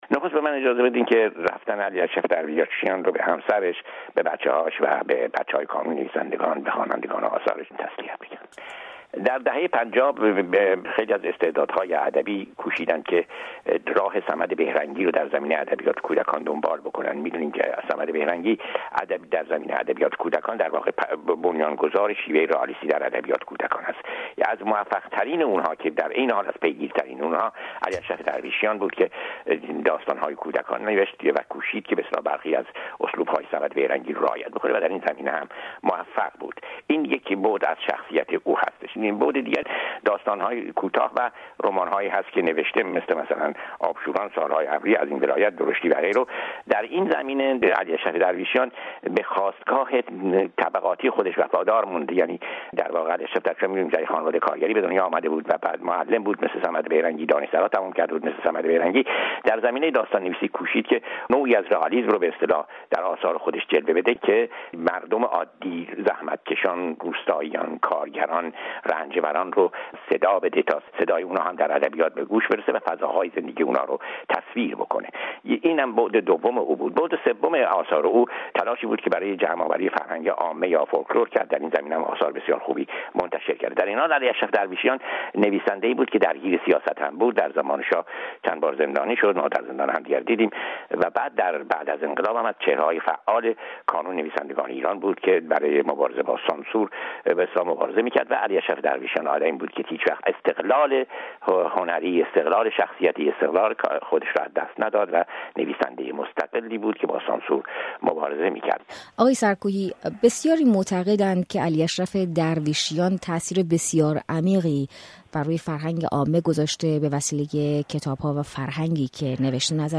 به مناسبت درگذشت این عضو کانون نویسندگان ایران، رادیو فردا با فرج سرکوهی، نویسنده مقیم آلمان، گفتگو کرده است